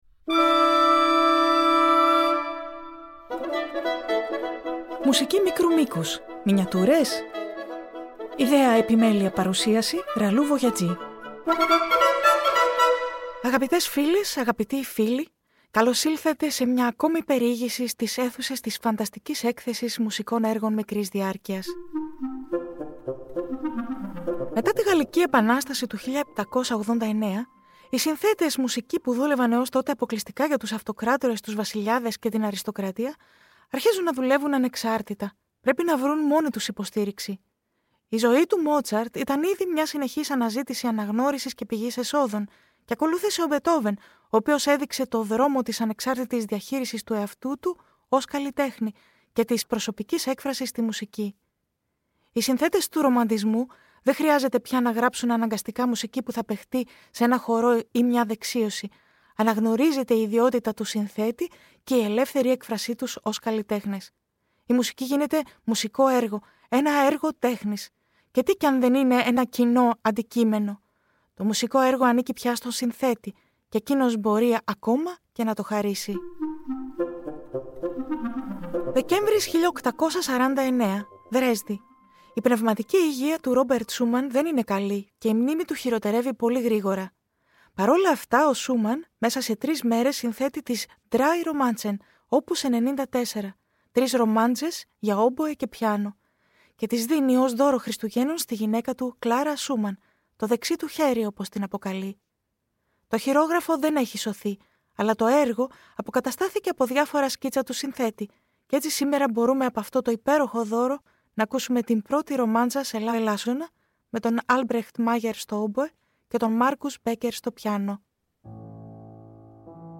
Η ‘’Μουσική μικρού μήκους-Μινιατούρες;‘’ στις καθημερινές μικροσκοπικές και εβδομαδιαίες θεματικές εκπομπές προσκαλεί σε περιηγήσεις σε μια φανταστική έκθεση- σε μια ‘’ιδιωτική συλλογή’’ -μουσικών έργων ‘’μικρού μήκους’’ από όλες τις εποχές και τα είδη της αποκαλούμενης δυτικής ευρωπαϊκής λόγιας και συγγενών ειδών: